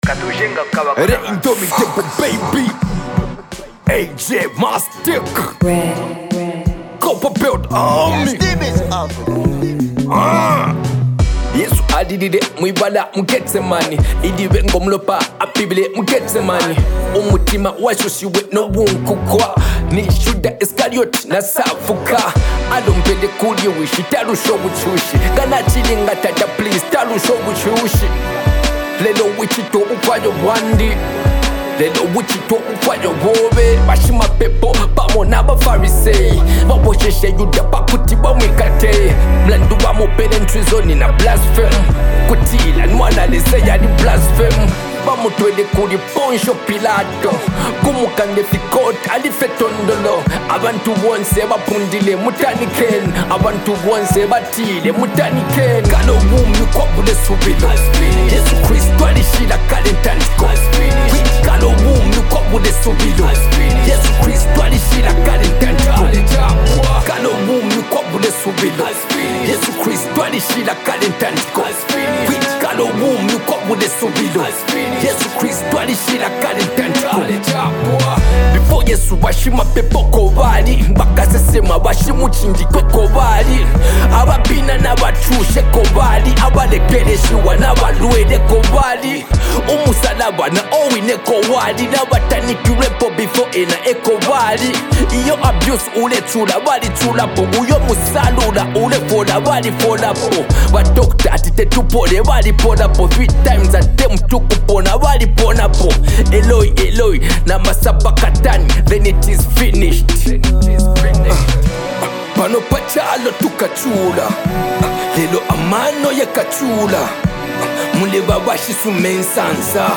Musical Excellence
A harmonious blend of soulful melodies, modern gospel beats